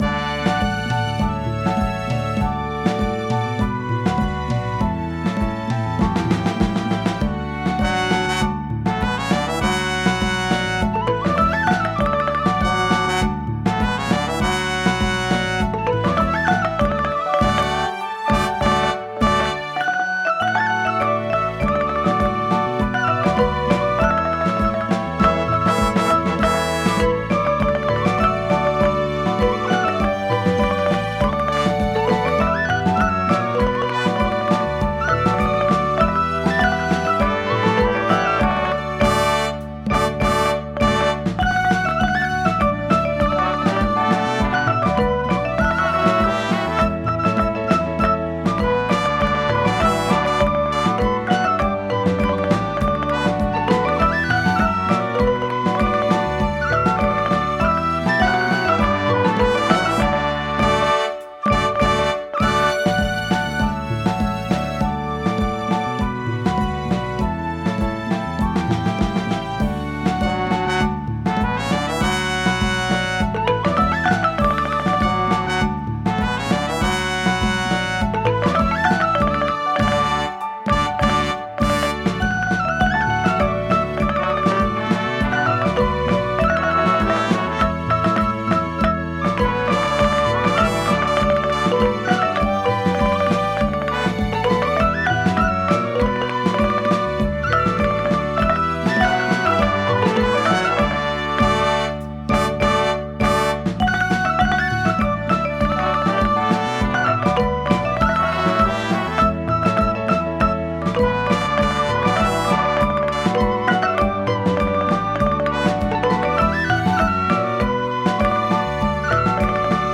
Backing Track